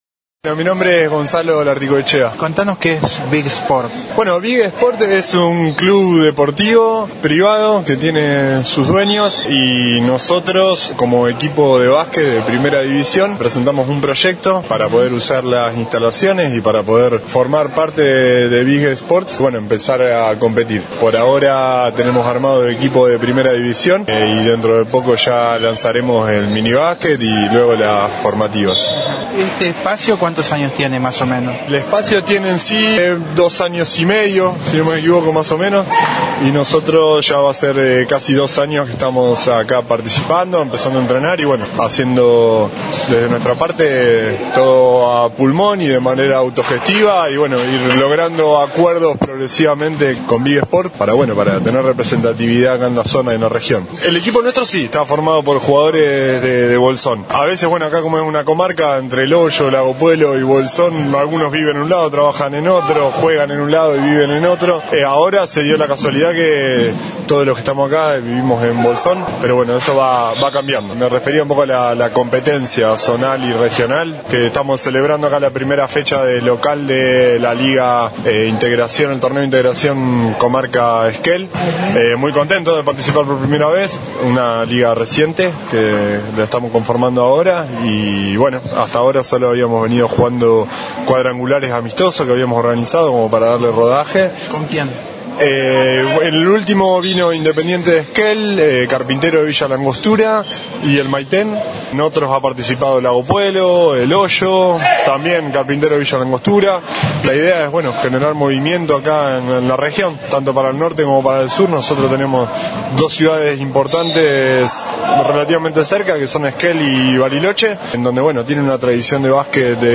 Noticias de Esquel fue el único medio de nuestra ciudad presente en El Bolsón, cubriendo estos partidos